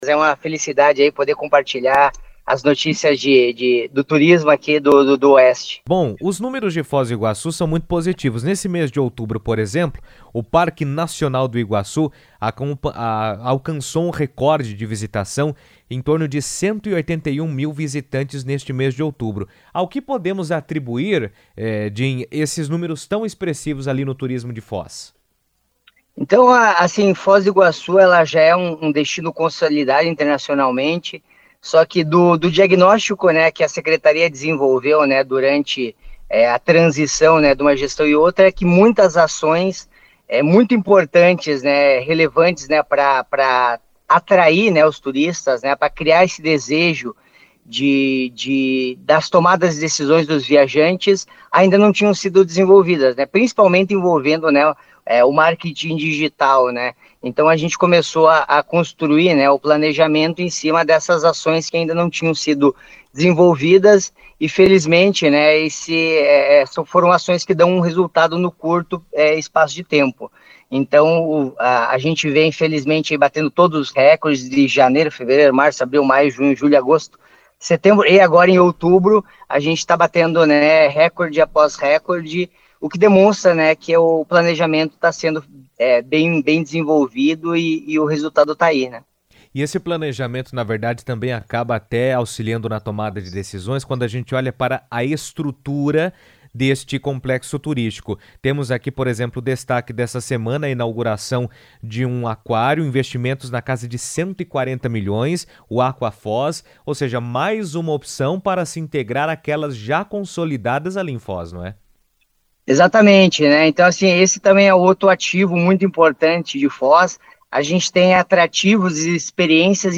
Foz do Iguaçu atingiu um recorde histórico de visitantes em outubro, com mais de 181 mil pessoas passando pelo Parque Nacional do Iguaçu, consolidando a cidade como um dos principais destinos turísticos do país e também como polo de eventos e negócios no Paraná. O secretário de Turismo, Jin Bruno Petrycoski, comentou sobre o crescimento e os impactos positivos do turismo para a cidade durante entrevista à rádio CBN, destacando ainda a expectativa de retomada da rota aérea entre Foz do Iguaçu e Lima, que deve fortalecer o intercâmbio internacional.